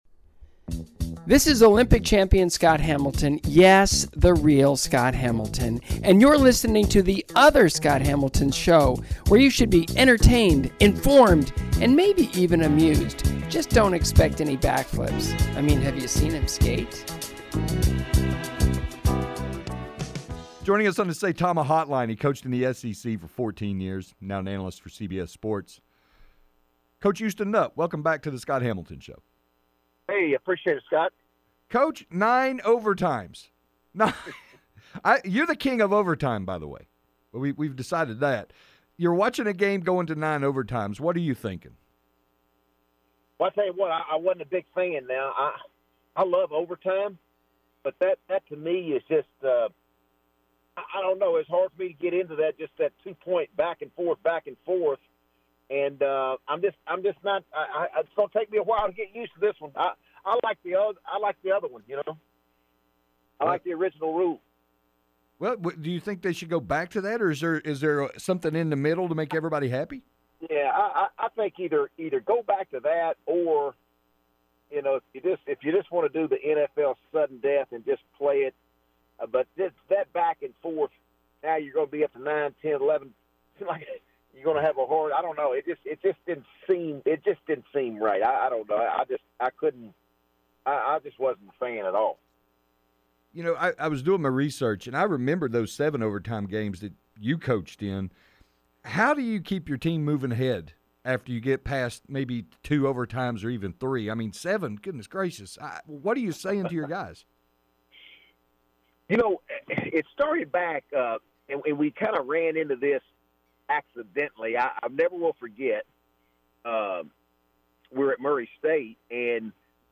Houston Nutt Interview